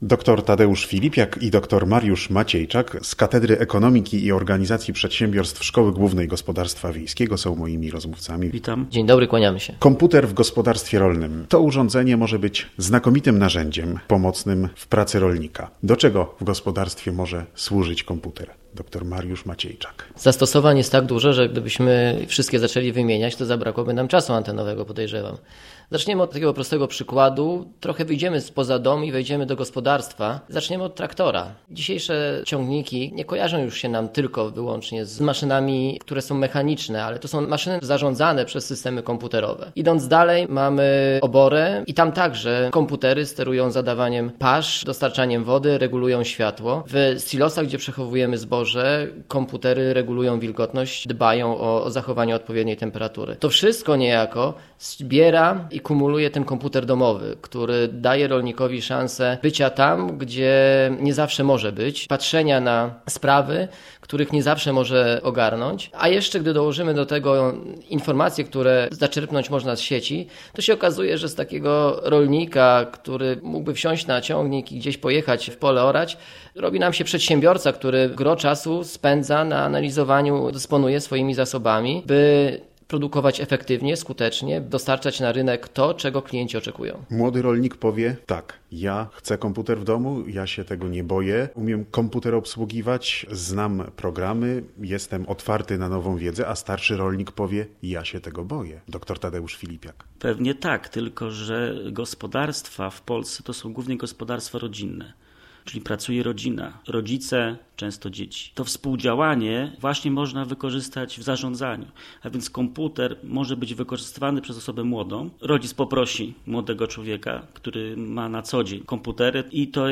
[wywiad]
komputery_fapa_radio_podlasie.mp3